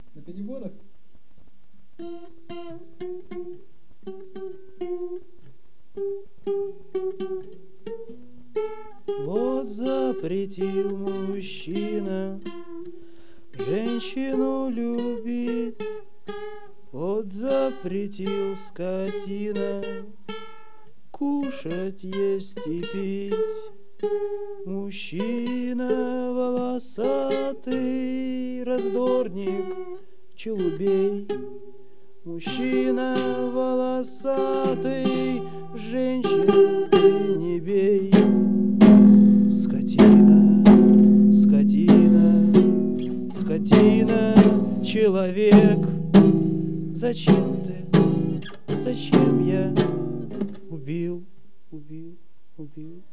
Мандолина и лежащий человек.